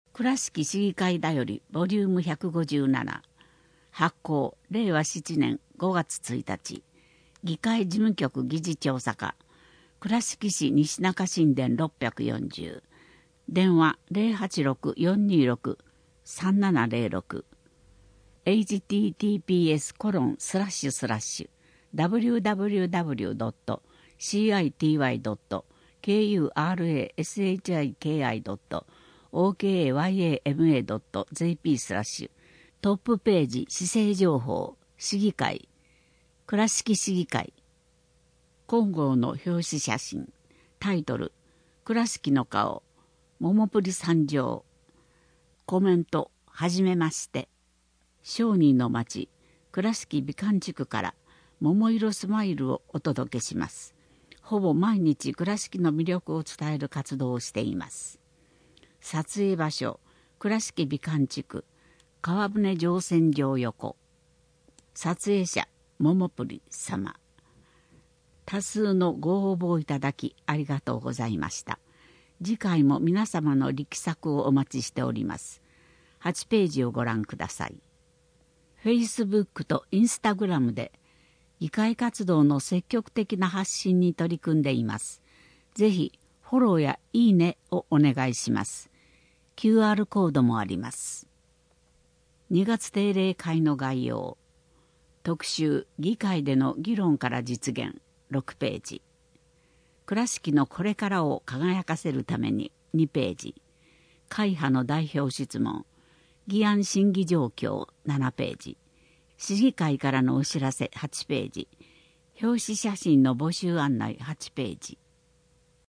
令和7年度5月号（2月定例会）声の議会だより